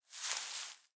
hay_impact_00.wav